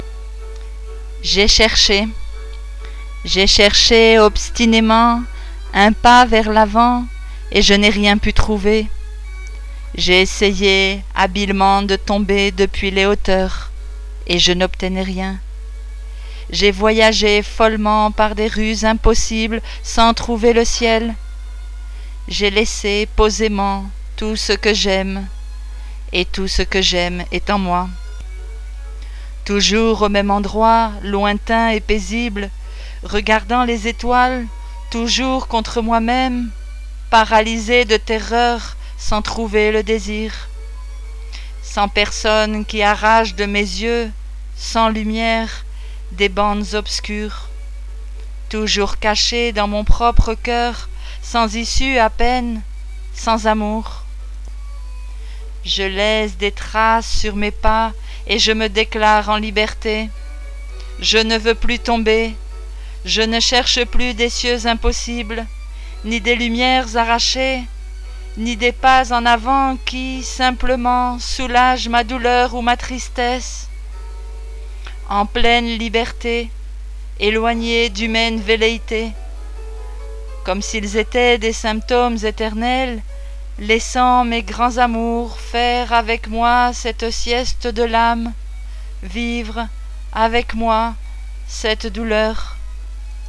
récite